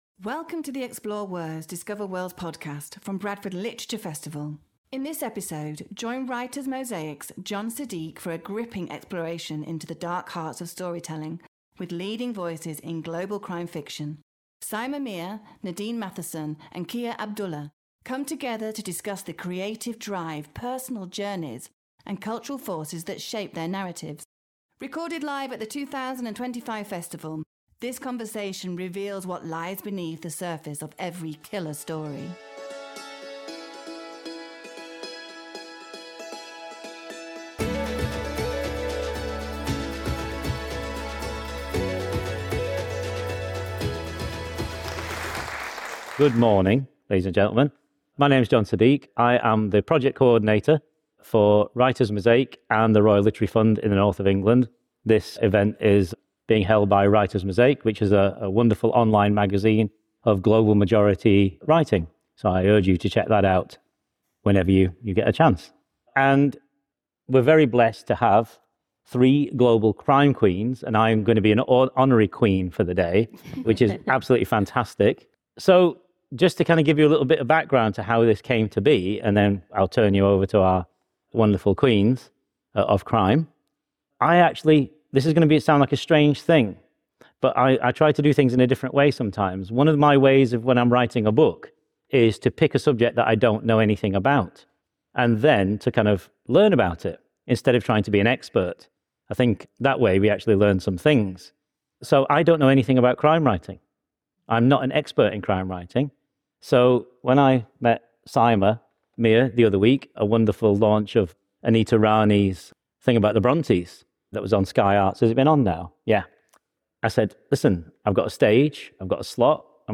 From vengeance to justice, and trauma to empowerment, this conversation reveals what lies beneath the surface of every killer story.